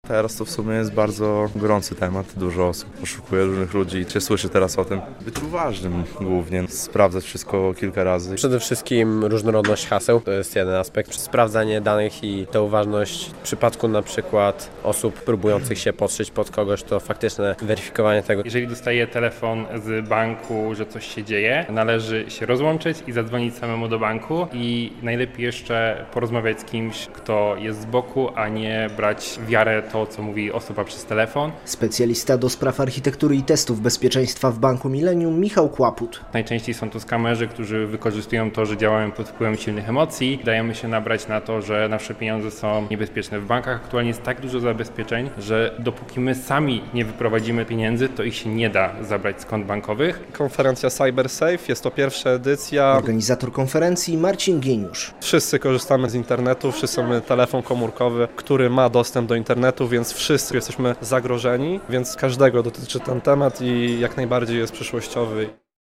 Dzień Bezpiecznego Internetu na Wydziale Informatyki Politechniki Białostockiej - relacja